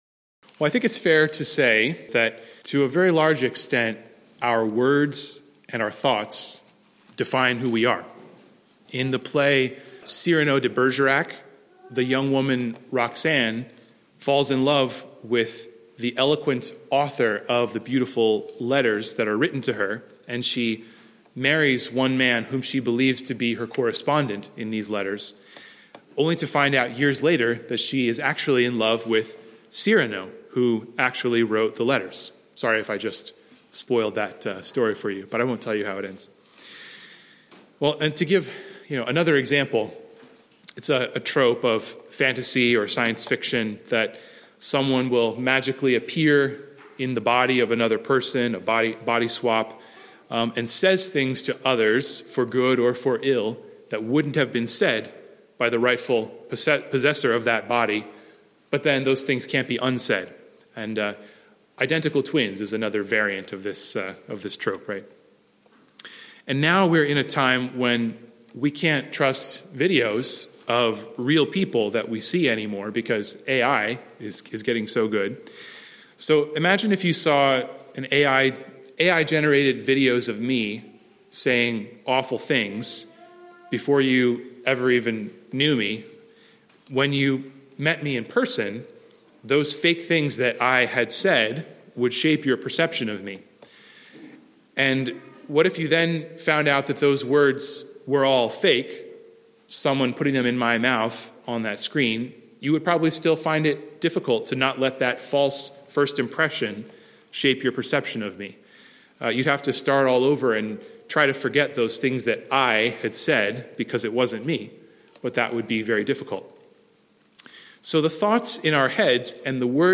Sermon Text: Matthew 5:21–22; 12:33–37